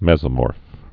(mĕzə-môrf, mĕs-)